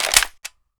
m4a1_clipin.mp3